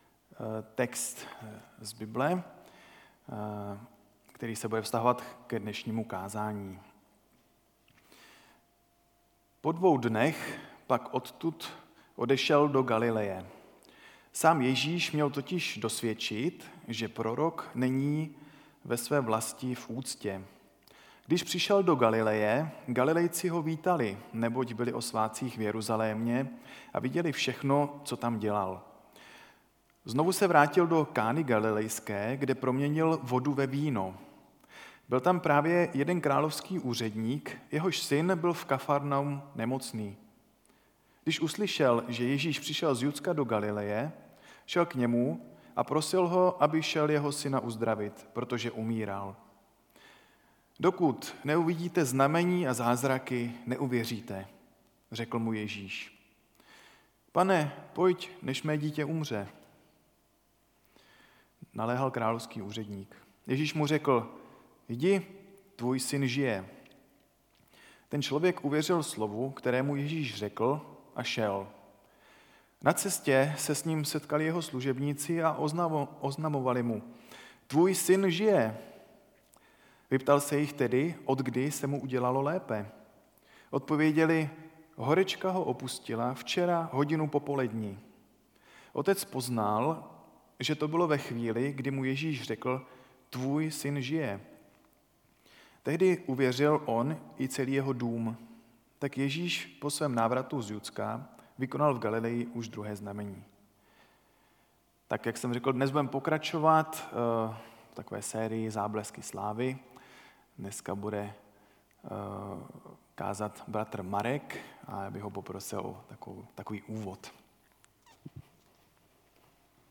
10. kázání ze série Záblesky slávy (Jan 4,43-54)